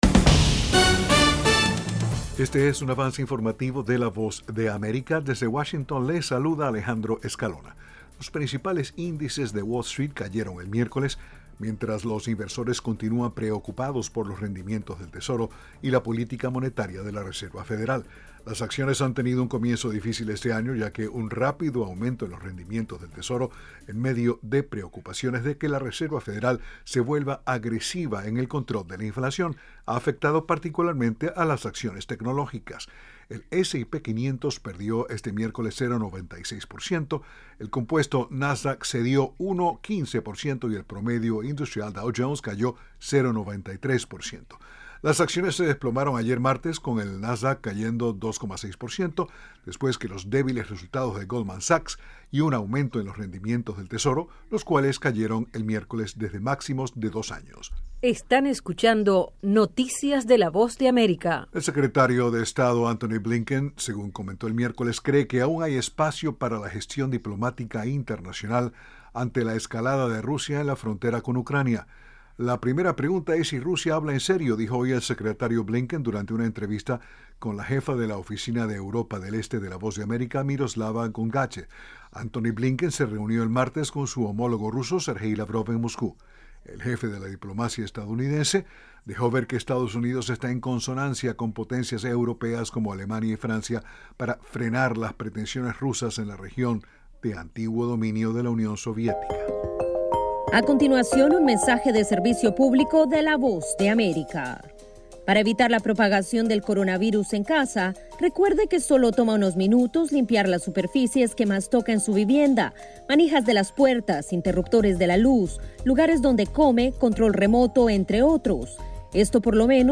Avance Informativo 6:00pm